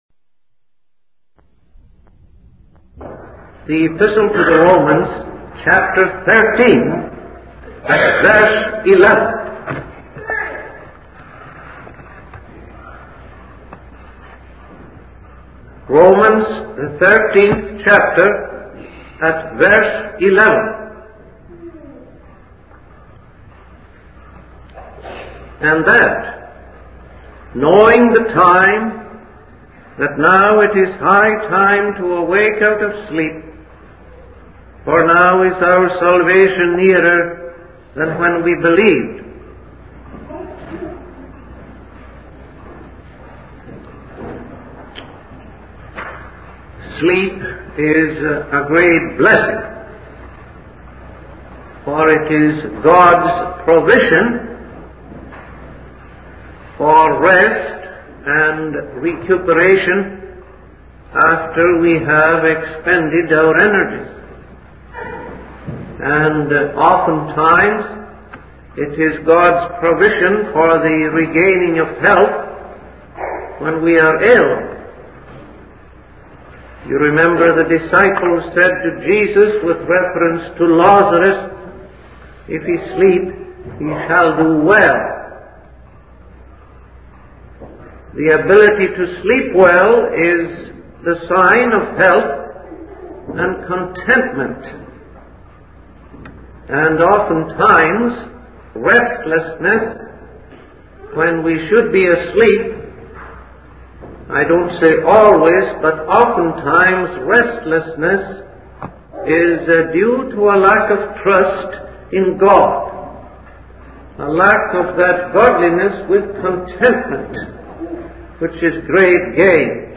In this sermon, the preacher focuses on the exhortation given by the apostle Paul to awake out of sleep. He emphasizes that the salvation of the people of God is the hope and ultimate goal.